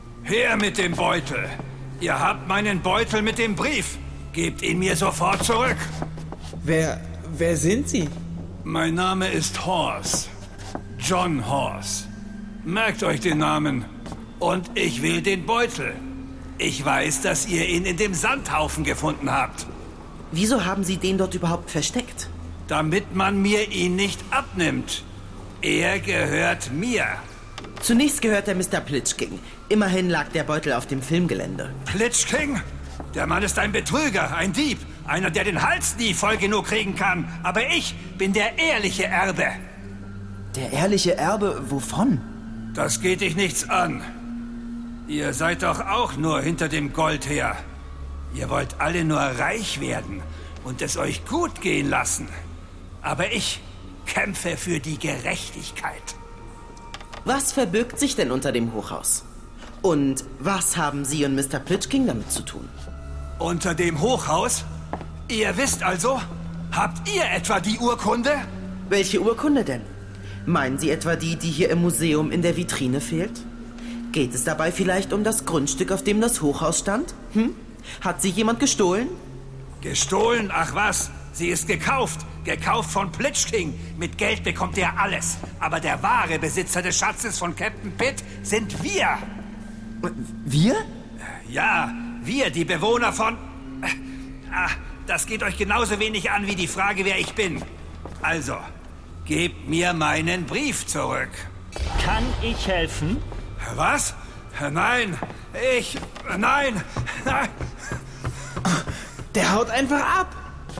sehr variabel
Alt (50-80)
Audio Drama (Hörspiel)